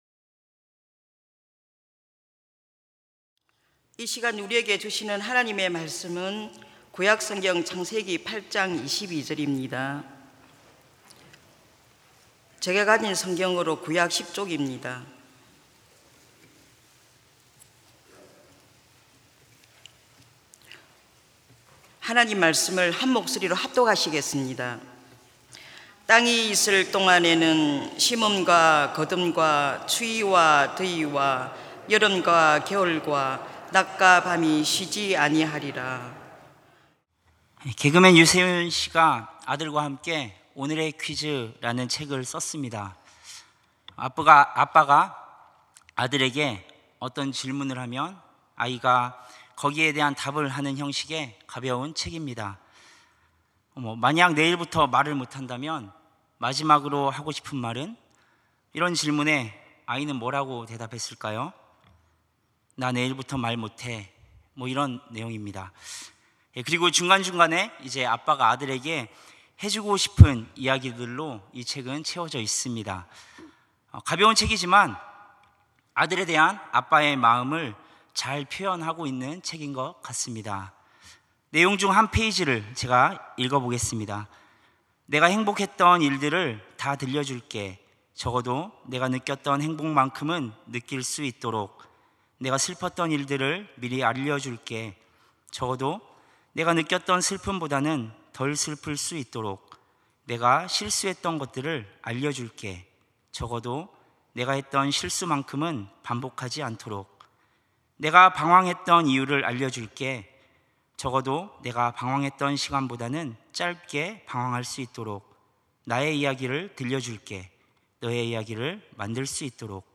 찬양예배 - 은혜